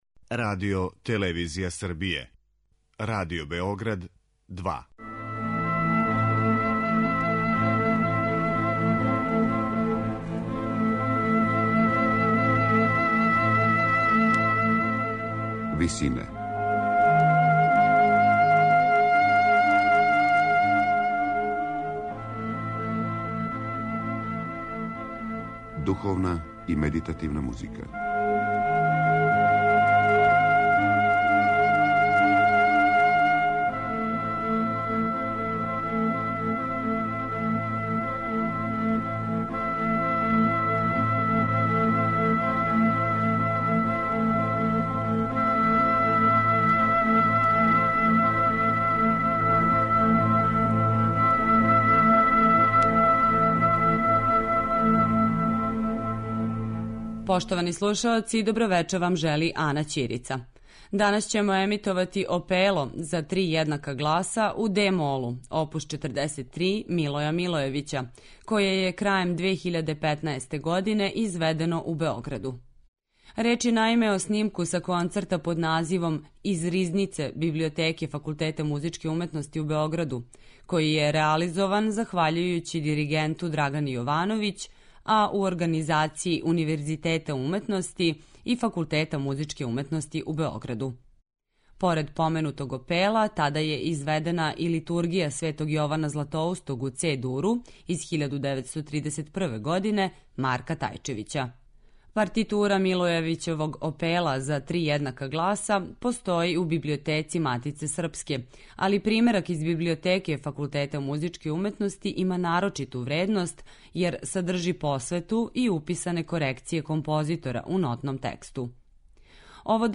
Емисија посвећена духовној и медитативној музици